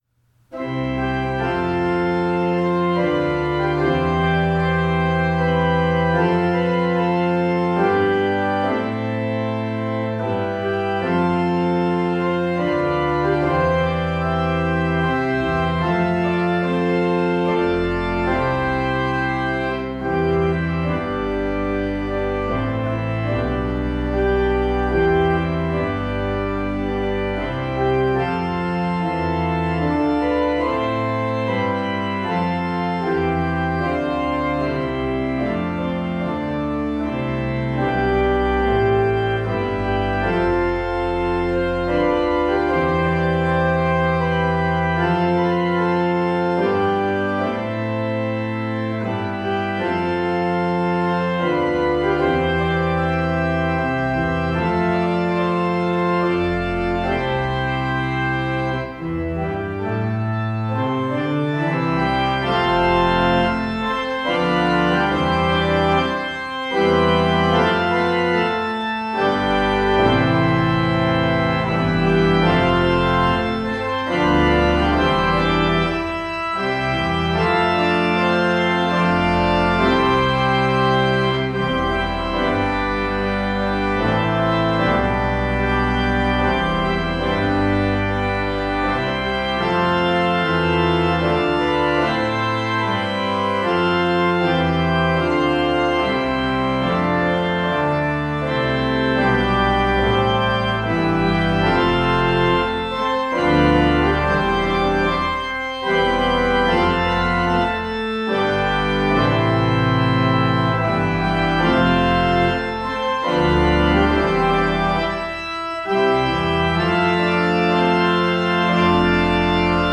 We’ve prepared this page to help you choose organ music for your wedding ceremony.
A. Grand & Glorious